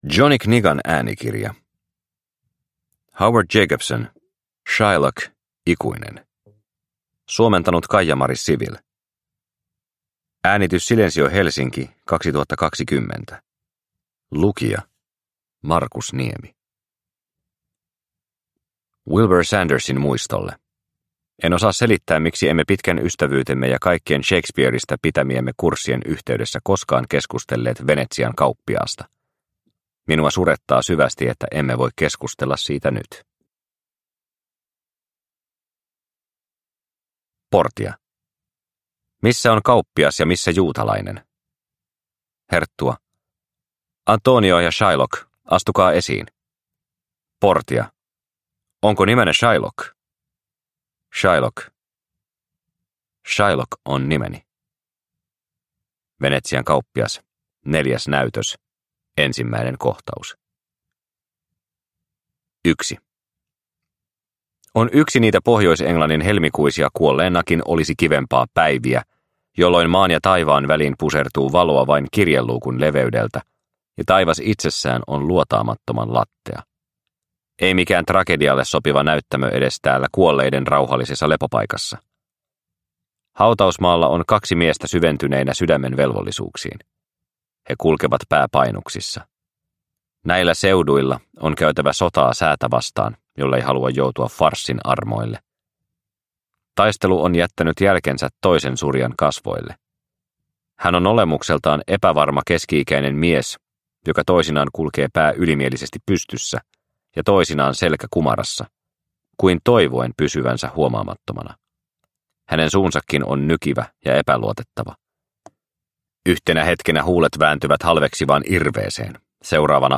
Shylock, ikuinen – Ljudbok – Laddas ner